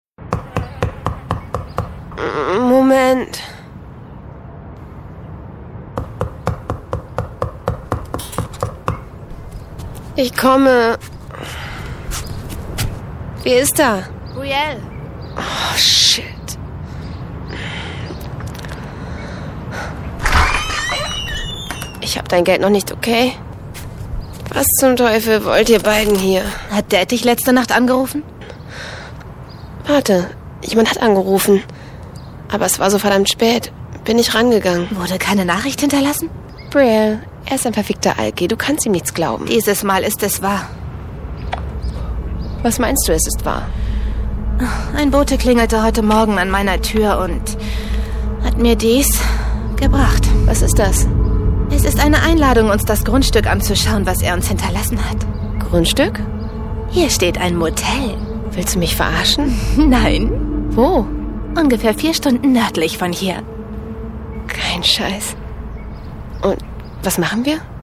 Professionelle Sprecherin und Schauspielerin
Sprechprobe: Industrie (Muttersprache):
german female voice over artist, young voice